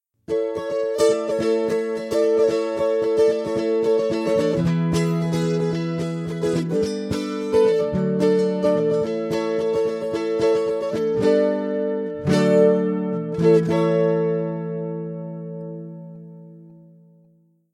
Bouzouki
Here are the two acoustics and bouzouki tracks.